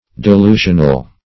Delusional \De*lu"sion*al\, a.